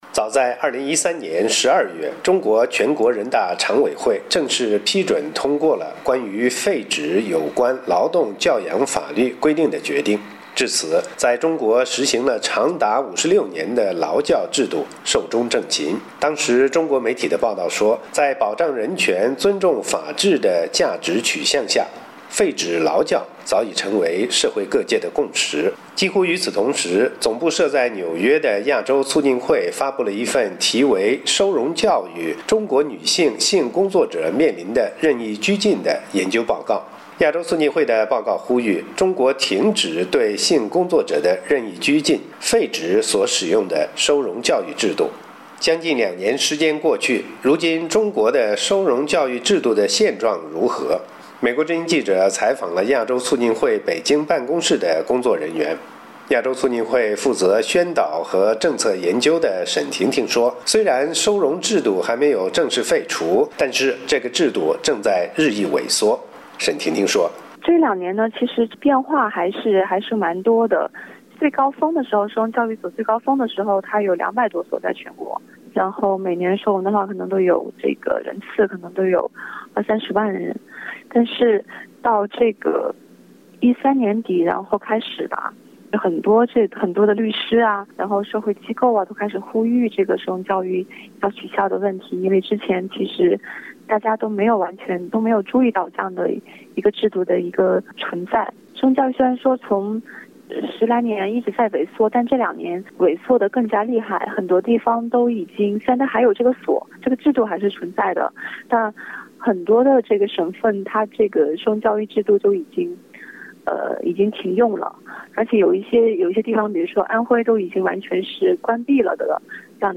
美国之音记者还通过电话采访了北方某大城市性工作者权益的代表。